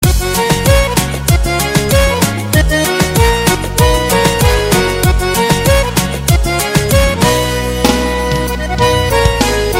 детские